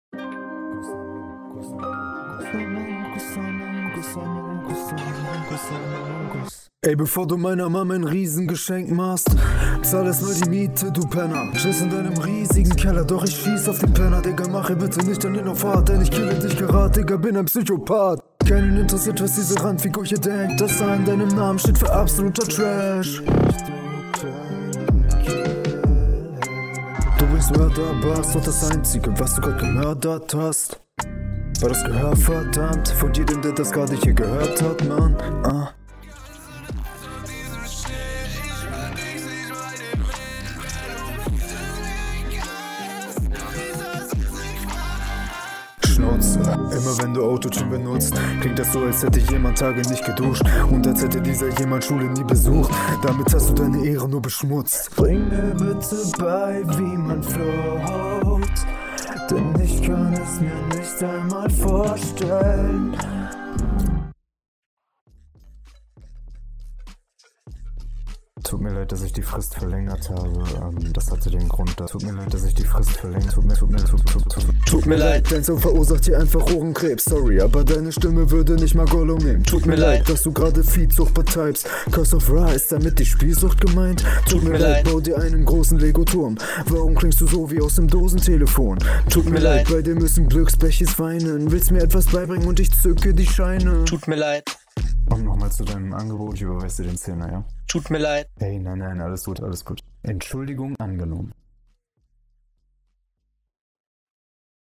Einstieg kommt richtig fett, dann bisschen weniger, dann wieder cool.